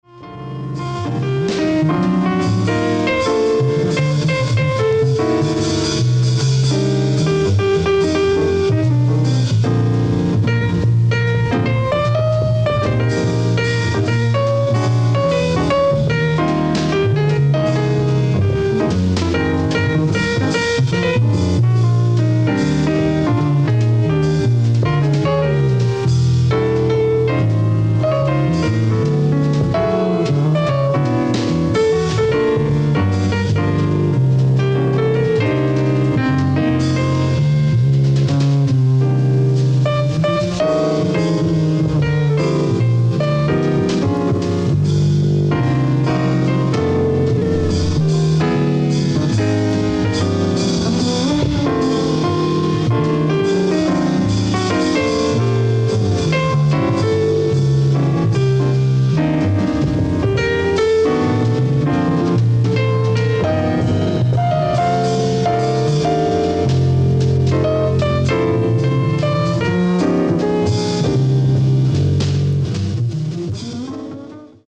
ライブ・アット・バルセロナ、スペイン 11/03/1985
※試聴用に実際より音質を落としています。